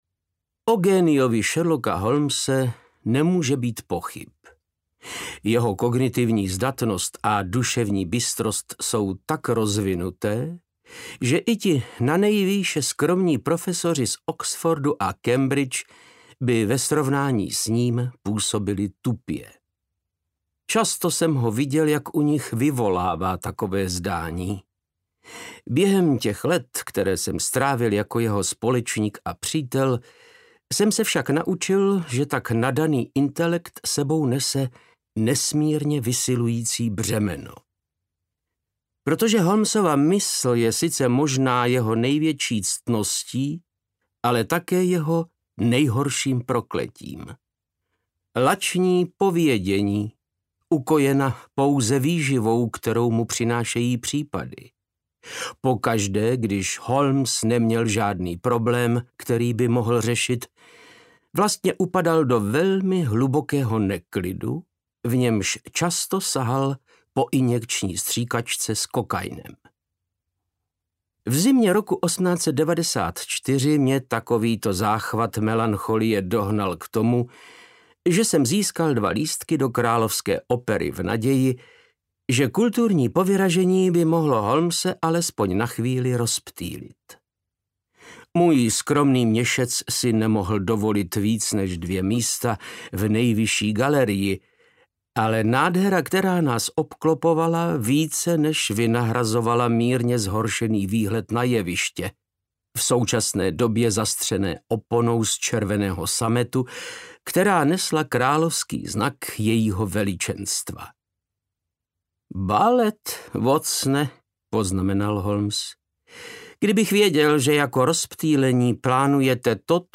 Sherlock Holmes a Nesmrtelný muž audiokniha
Ukázka z knihy
• InterpretVáclav Knop